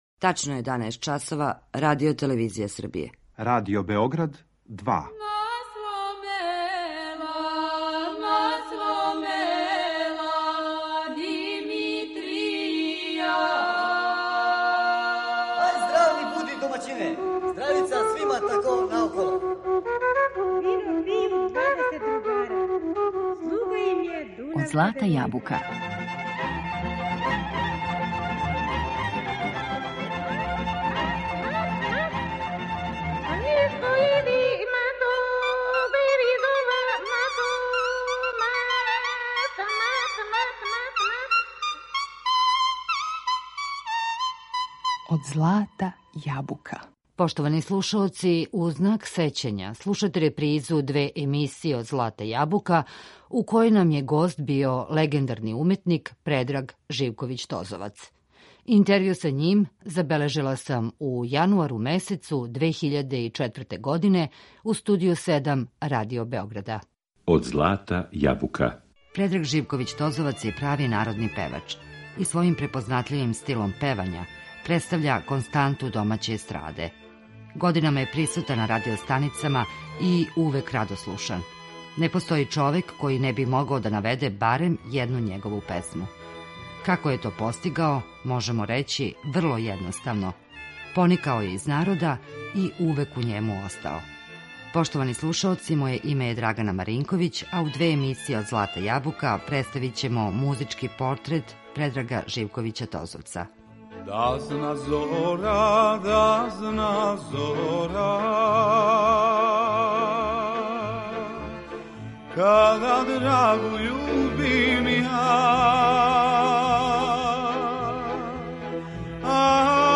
Razgovor je zabeležen u januaru 2004. godine, u Studiju 7 Radio Beograda.